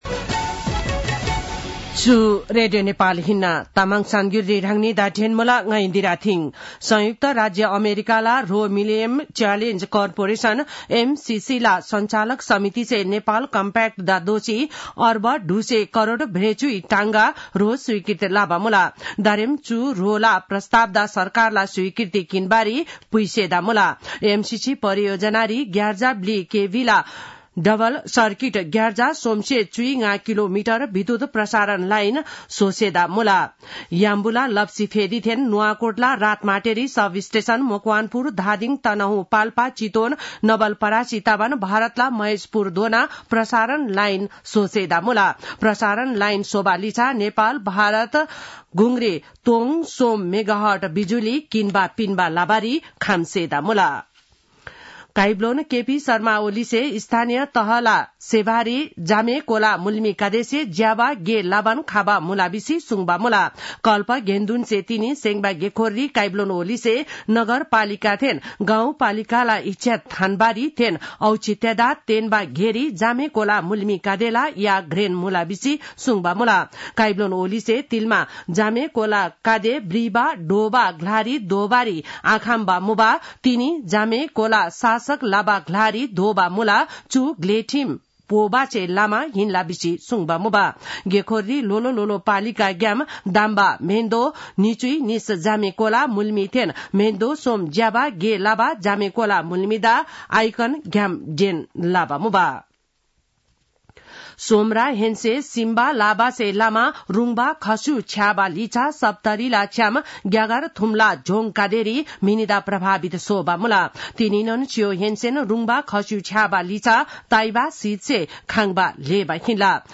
तामाङ भाषाको समाचार : २० पुष , २०८१
Tamang-news-9-19.mp3